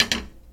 关于放置水杯音效的高质量PPT_风云办公